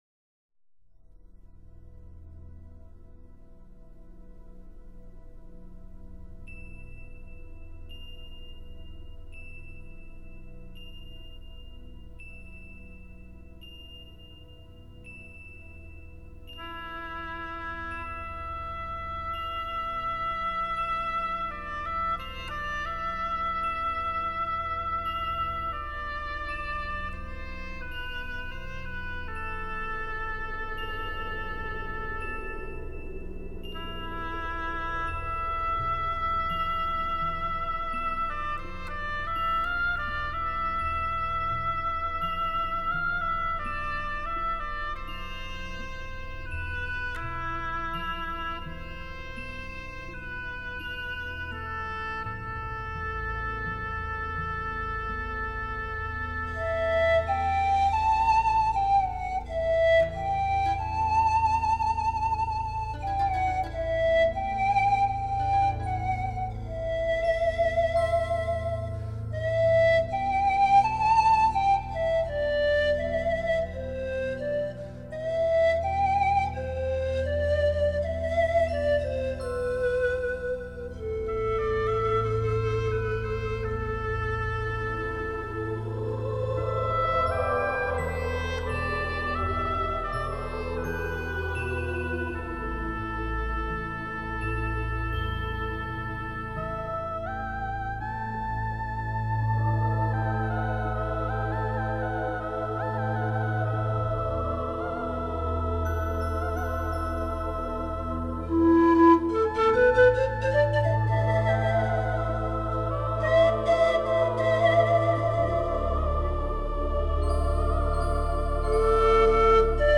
本辑是青海民歌改编的轻音乐。中国民族乐器为主奏，辅以西洋管弦乐器及电声乐器。